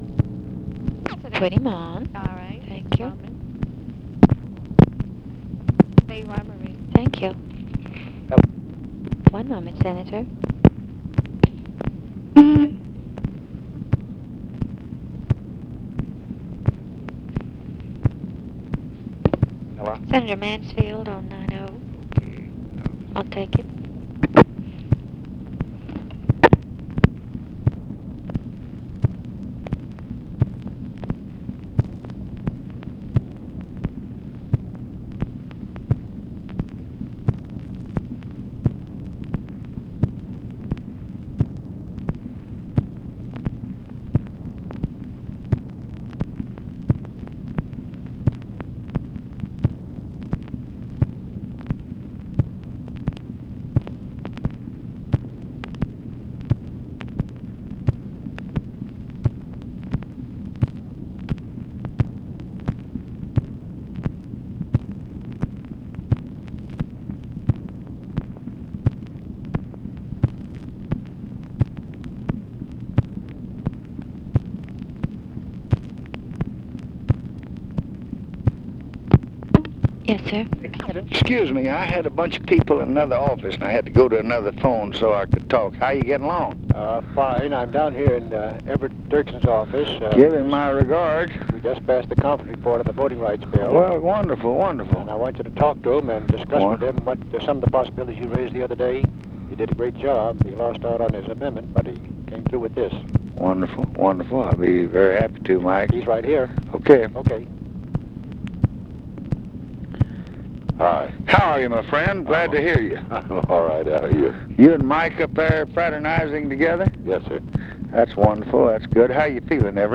Conversation with MIKE MANSFIELD and EVERETT DIRKSEN, August 4, 1965
Secret White House Tapes